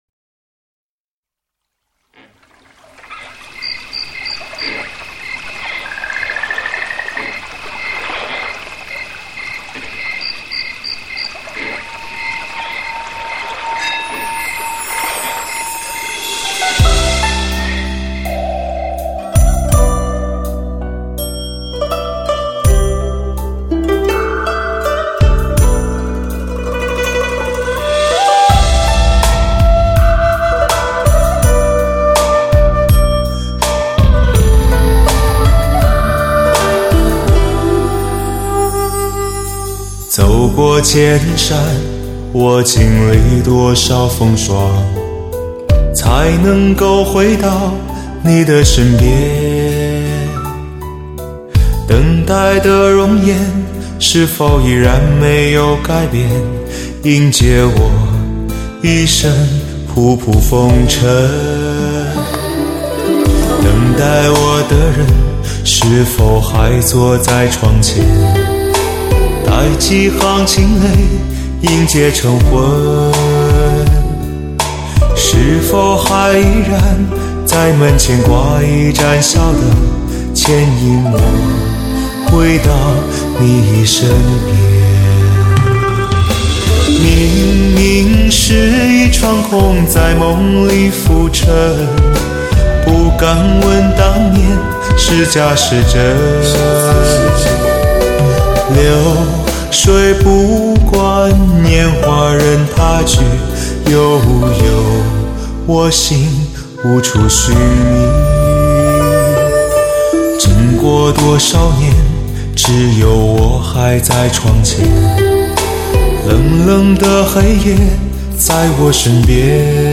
专辑格式：DTS-CD-5.1声道
示范级演绎录音，特别处理的音效技术，纯粹为汽车音乐而生。